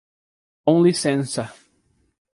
Pronúnciase como (IPA) /kõ liˈsẽ.sɐ/